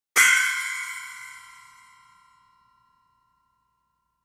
Ceng-ceng-single-sus.mp3